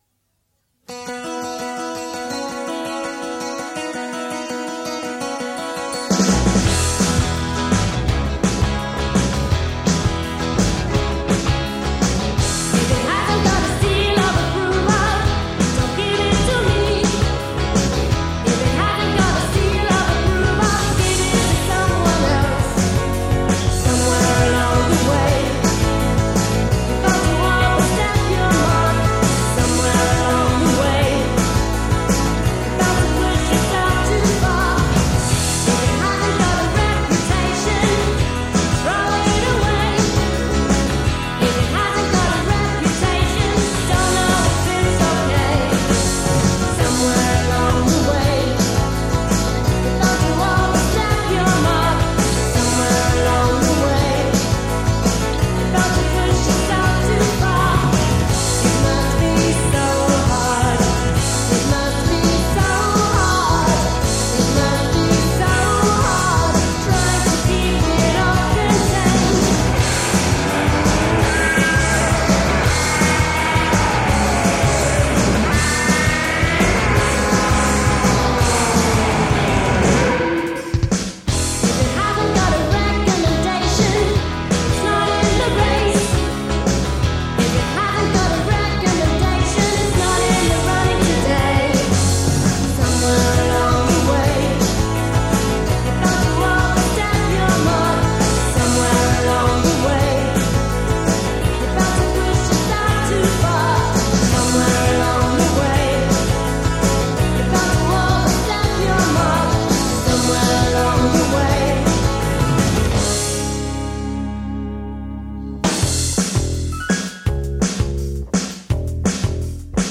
Possibly all hailing from the same demo tape.